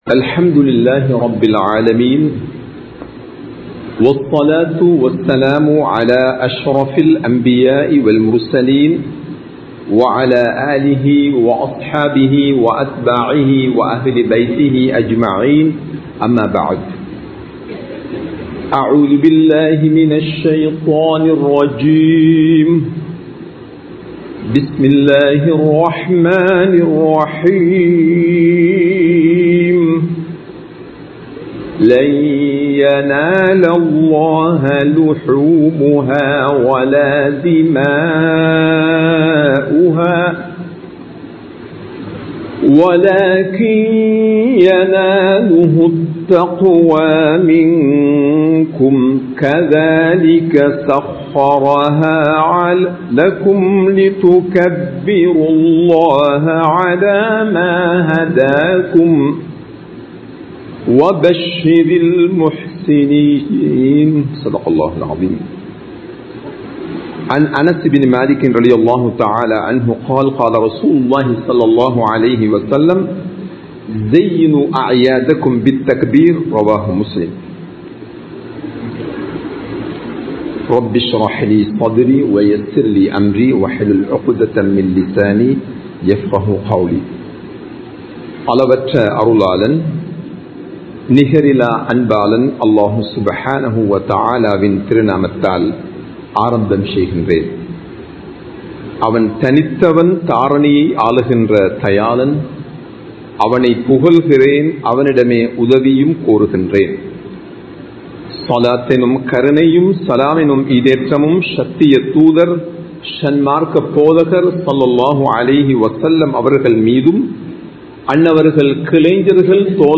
ஈதுல் அல்ஹாவின் சிறப்புகள் (Greatnesses of Eid ul Adha) | Audio Bayans | All Ceylon Muslim Youth Community | Addalaichenai